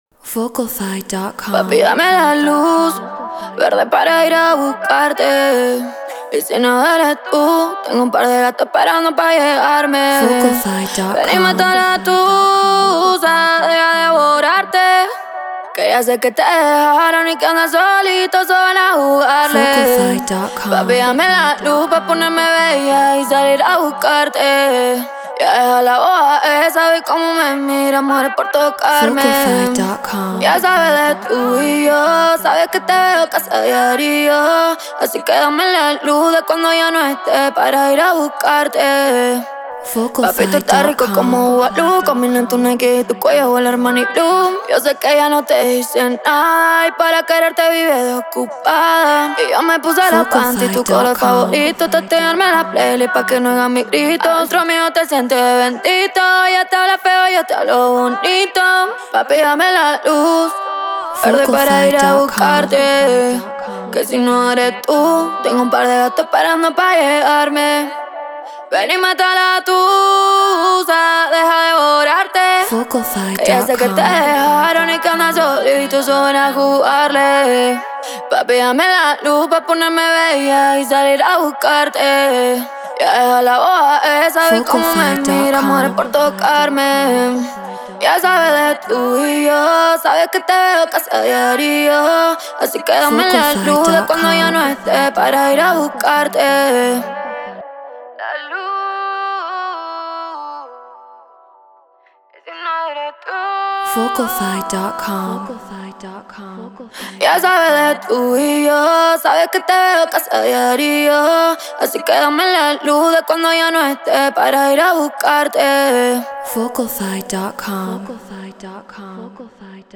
Reggaeton 100 BPM Bmin
RØDE NT1-A Focusrite Scarlett FL Studio Treated Room